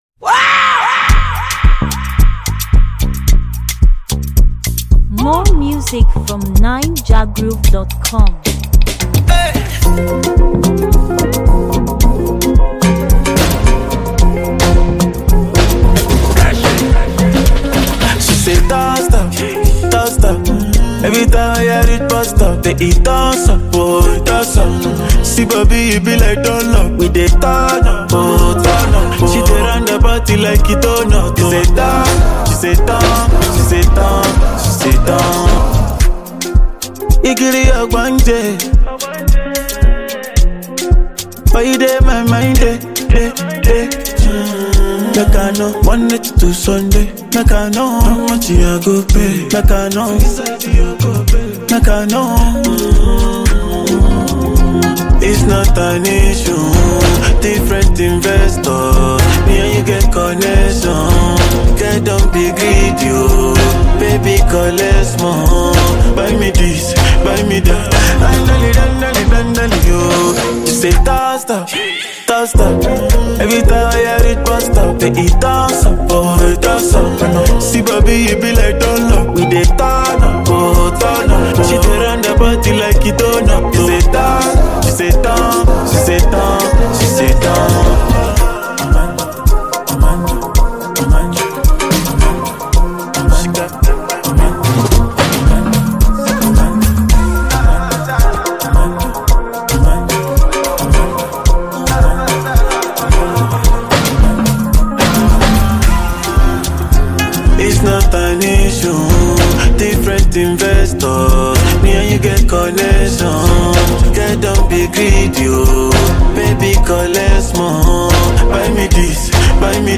Latest, African-music, Naija-music